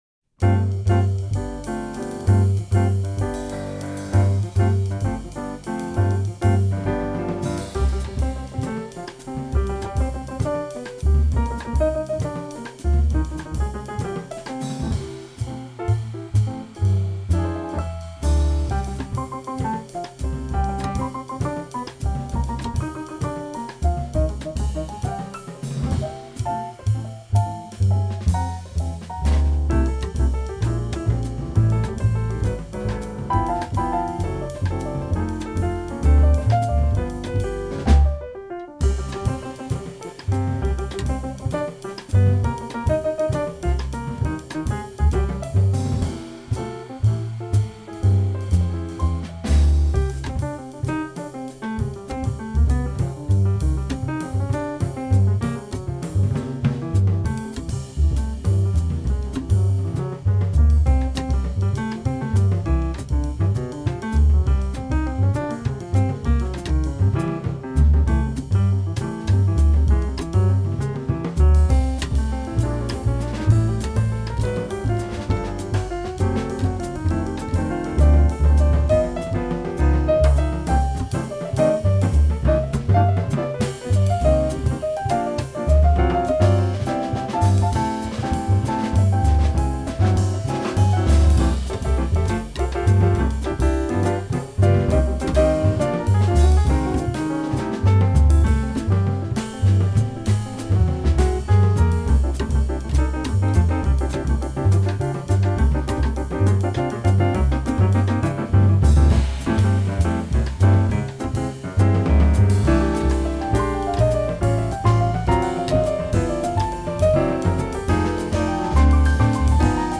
pianoforte
contrabbasso
batteria